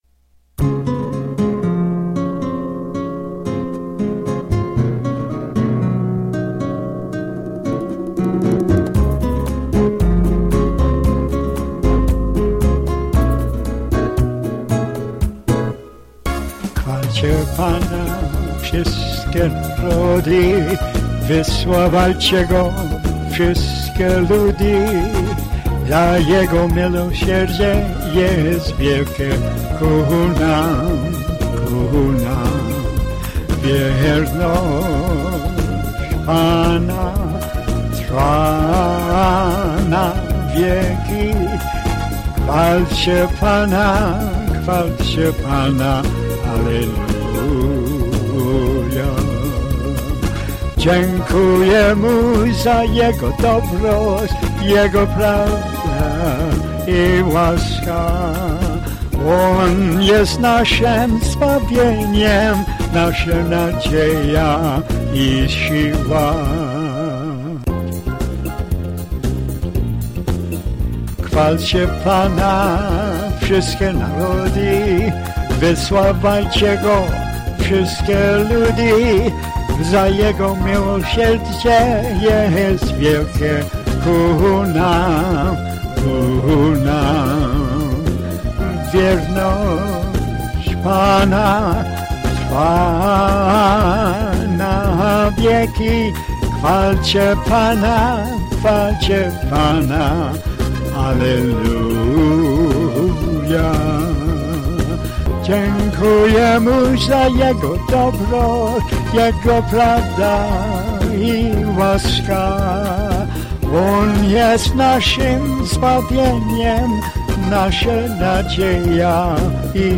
then recorded as a calypso